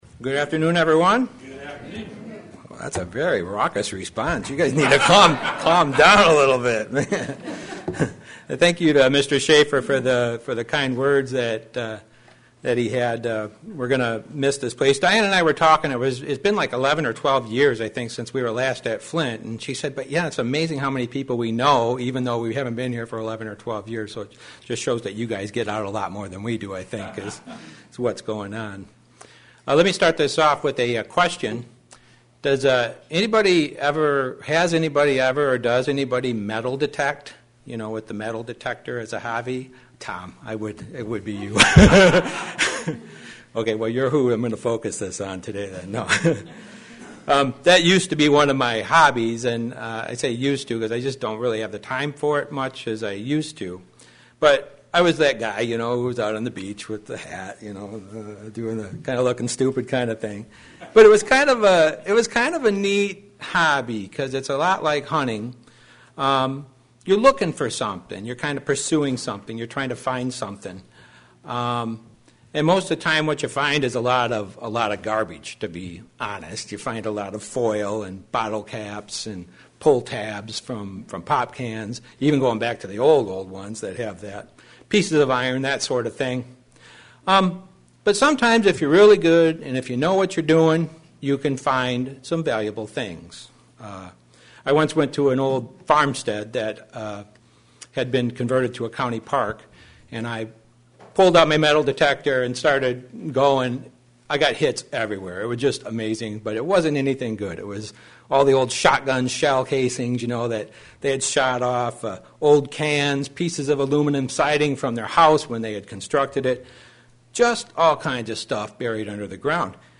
Given in Flint, MI
Likewise, buried junk/sin in our lives will make us unproductive Christians. sermon Studying the bible?